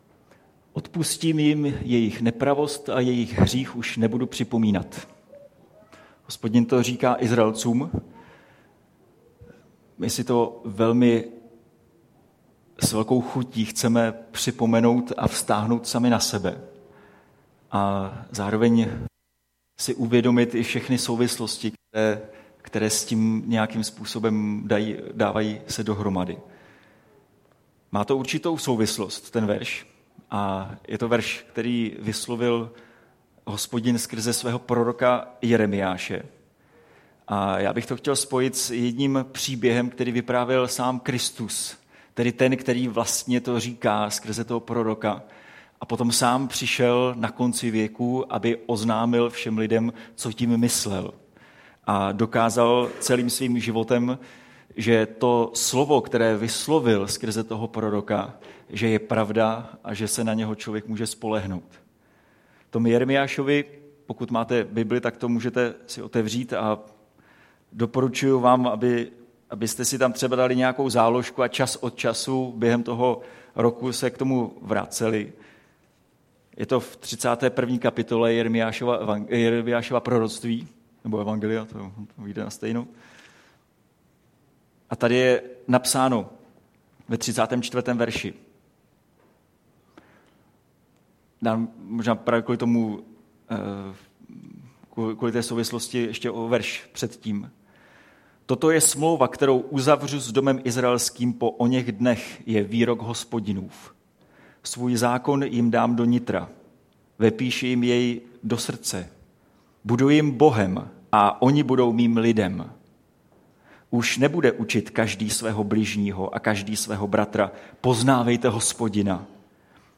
Kázání | Církev bratrská Havířov